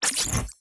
Computer Calculation Notificaiton 5.wav